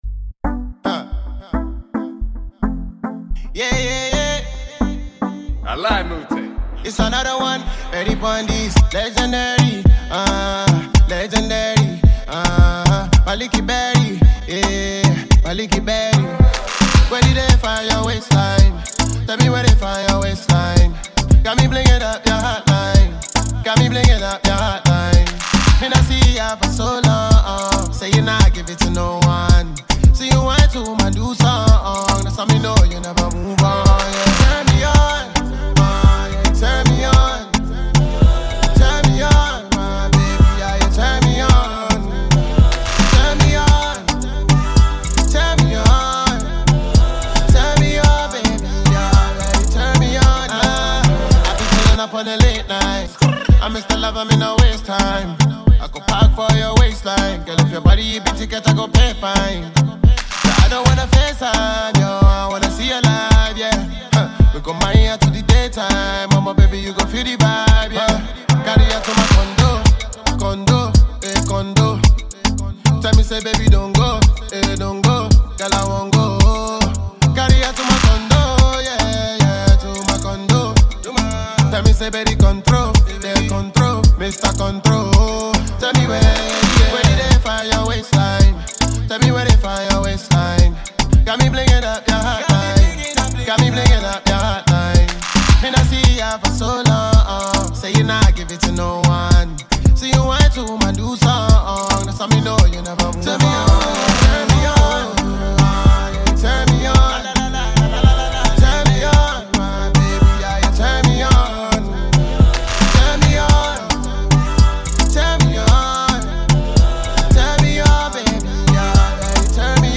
infectious score
upbeat Dancehall-esque record